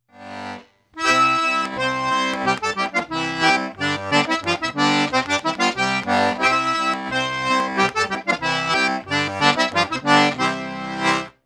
Video example of alternating octave notes up the scale
There are some right hand �tricks� of alternating between octaves.